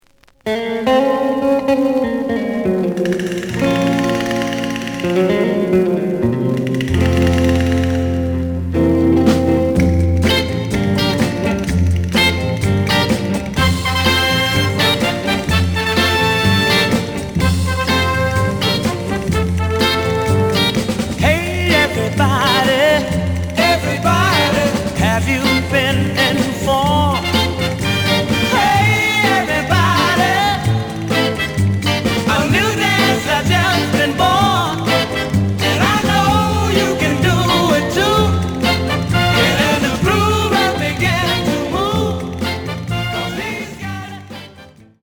The audio sample is recorded from the actual item.
●Genre: Soul, 60's Soul
Slight edge warp.
B side plays good.)